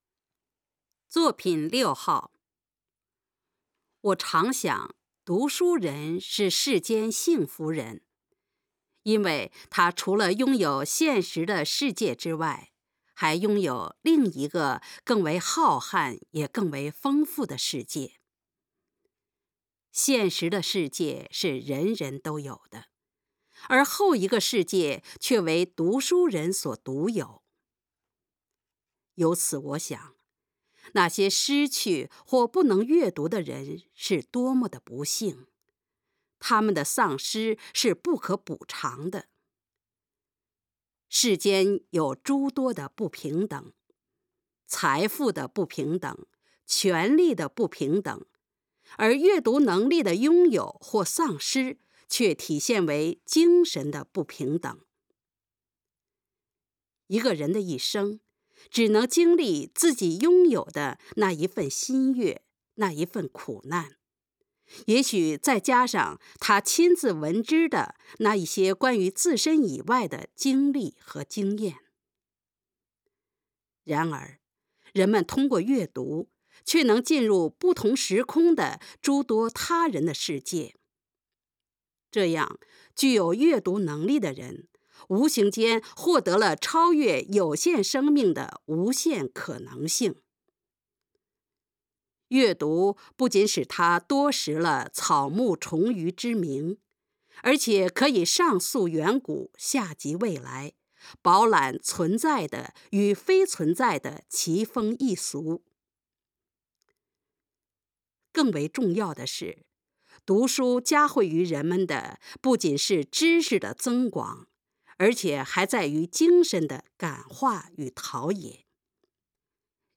朗读短文音频